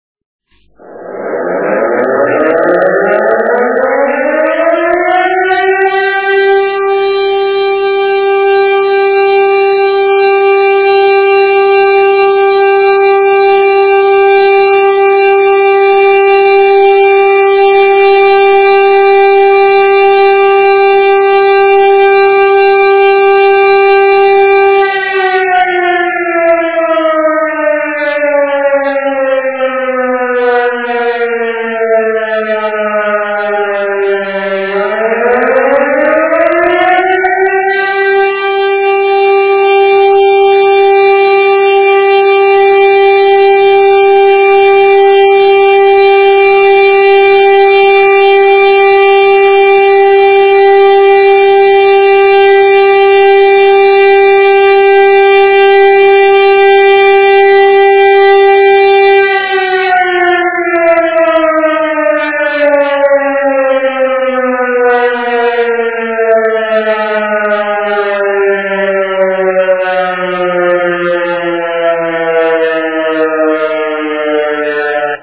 Požární poplach
Signál je vyhlašován přerušovaným tónem po dobu 60 sekund (25 sekund trvalý tón, 10 sekund přestávka, 25 sekund trvalý tón)
rotační siréna (akustický signál)
pozarni-rot.mp3